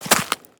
Sfx_creature_snowstalkerbaby_walk_07.ogg